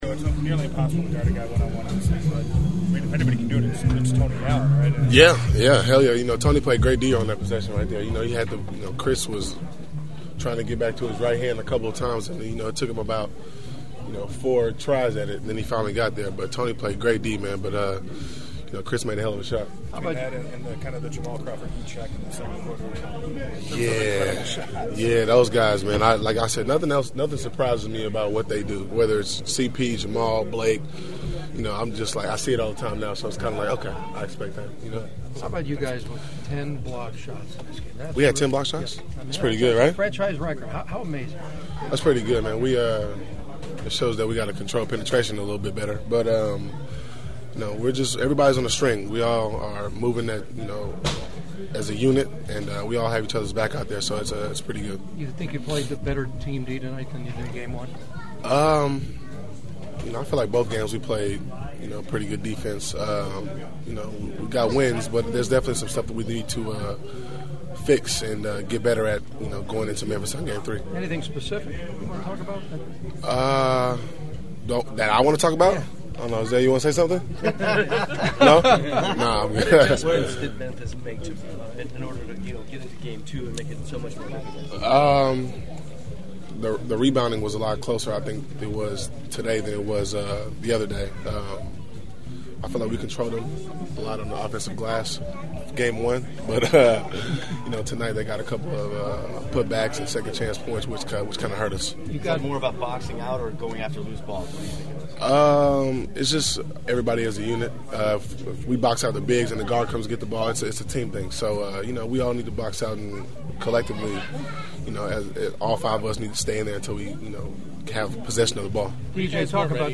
The following are my postgame Clipper interviews along with several preview thoughts for games 3 and 4 in Memphis on Thursday and Saturday (which you can hear of course on KFWB Newstalk 980).